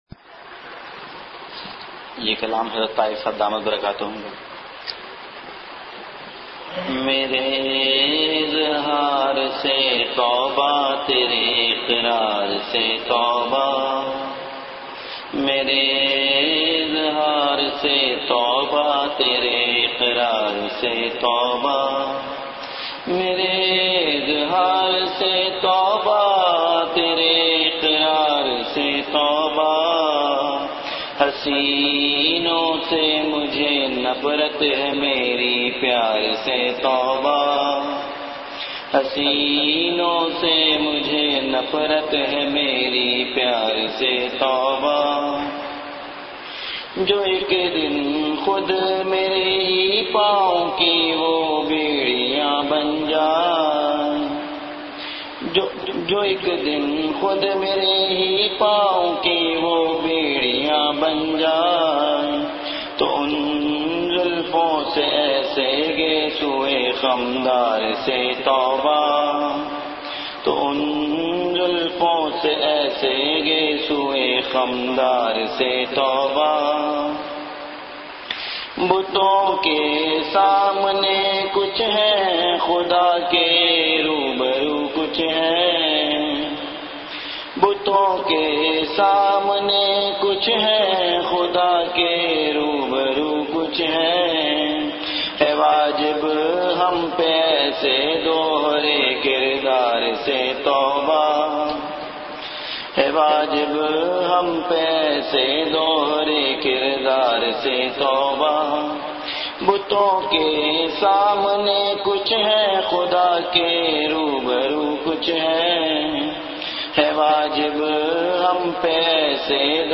Majlis-e-Zikr
Venue Home Event / Time After Isha Prayer